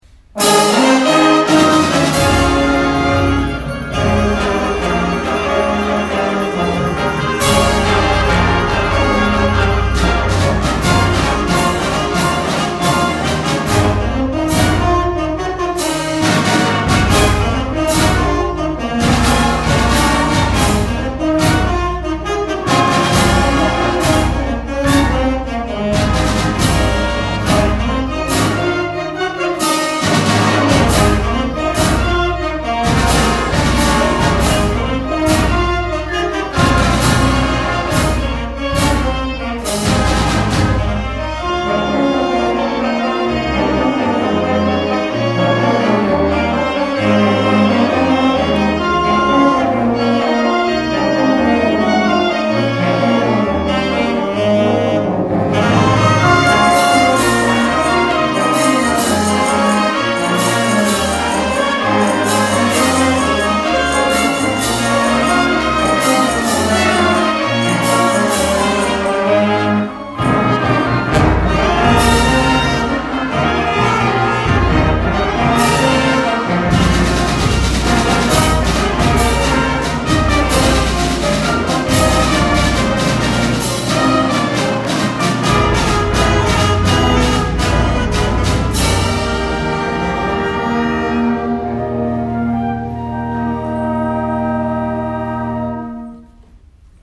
第１４回南那須地区音楽祭にブラスバンド部が出場しました。
出だしから全開の曲です。
迫力ある演奏をお聴きください。